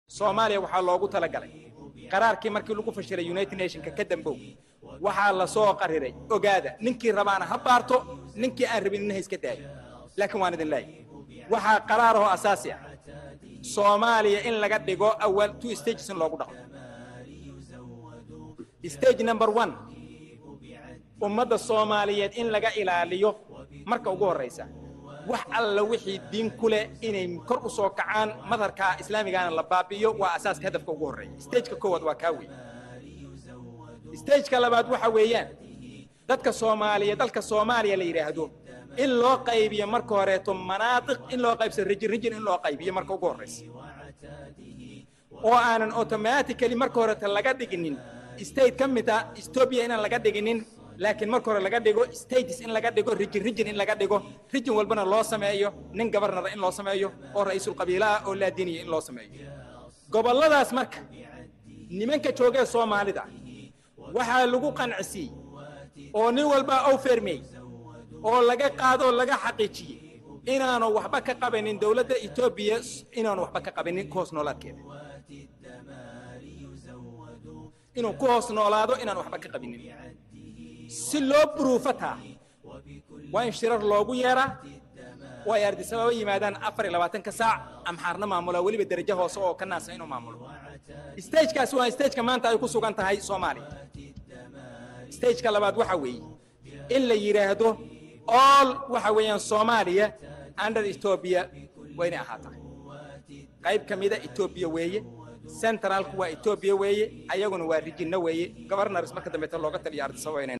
muxaadaro.